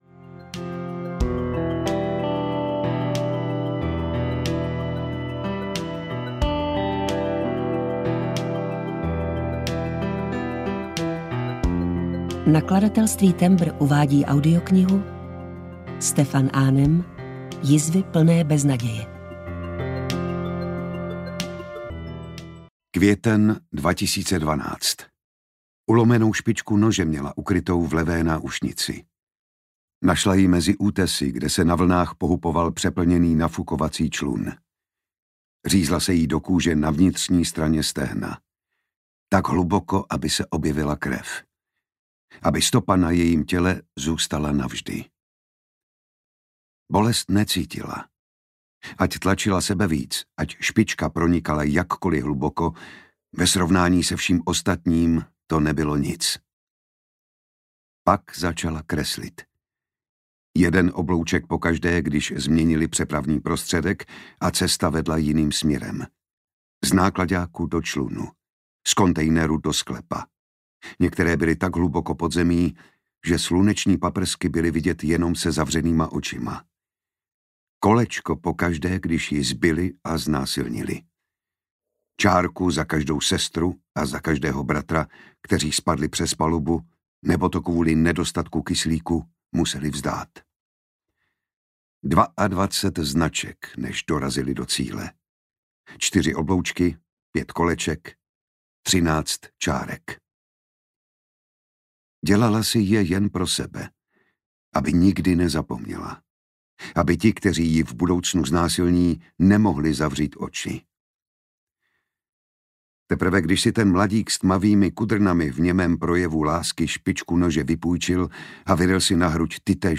Jizvy plné beznaděje audiokniha
Ukázka z knihy
• InterpretJan Šťastný, Dana Černá